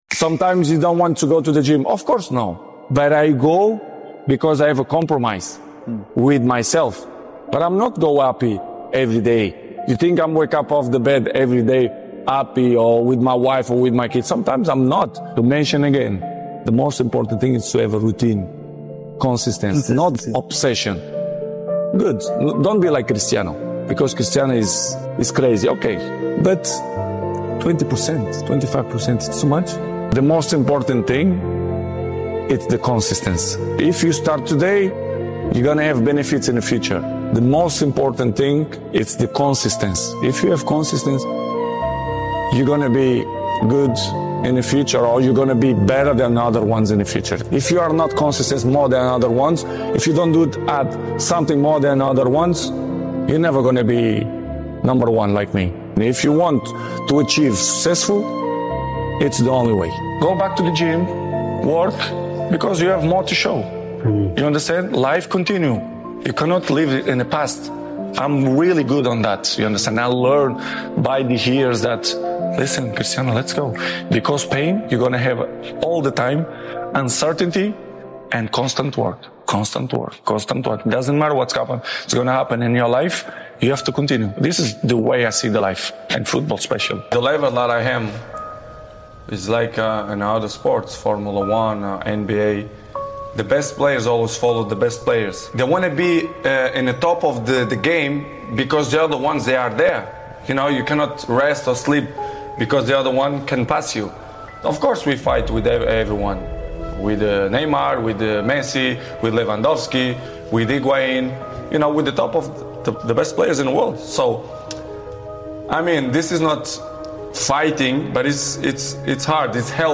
This Cristiano Ronaldo motivational speech isn’t just about football — it’s about life.